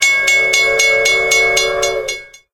troll_train_warn_01.ogg